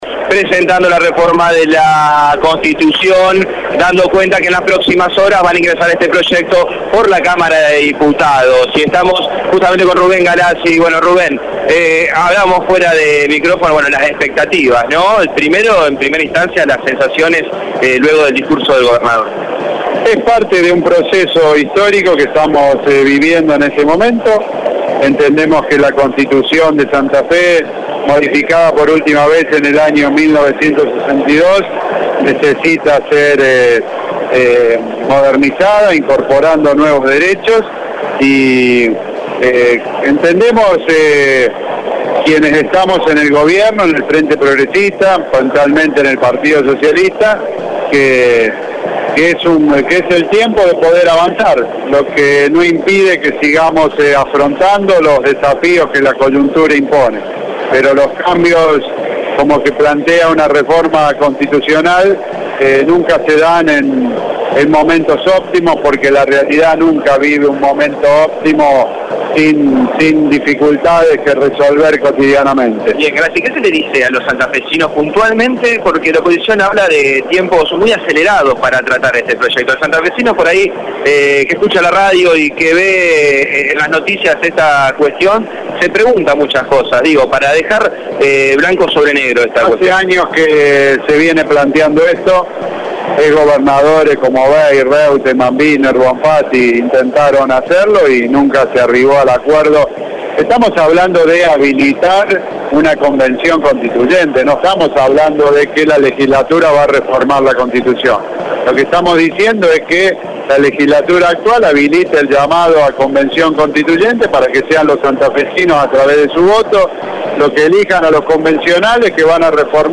RUBEN GALASII EN RADIO EME SOBRE LA REFORMA: